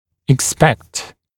[ɪk’spekt] [ek-][ик’спэкт] [эк-]ожидать, предполагать